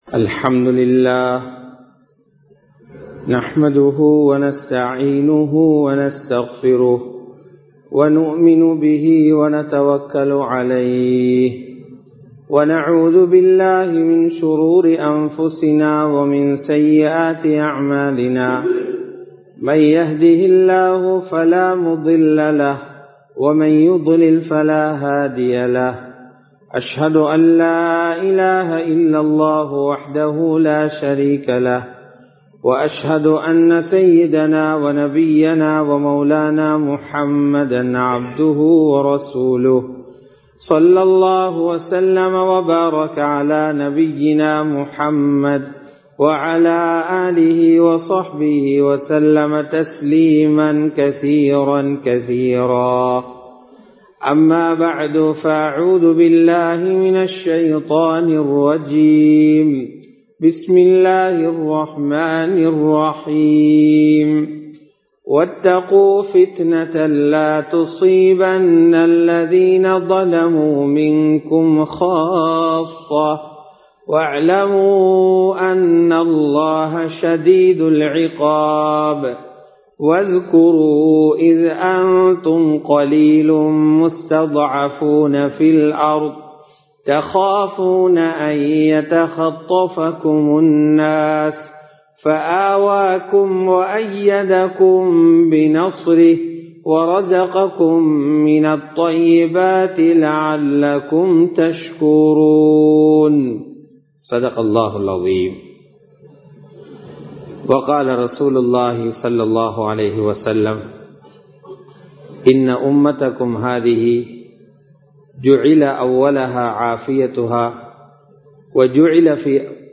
பாவங்களும் தௌபாவும் | Audio Bayans | All Ceylon Muslim Youth Community | Addalaichenai
Muhiyaddeen Grand Jumua Masjith